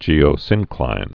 (jēō-sĭnklīn)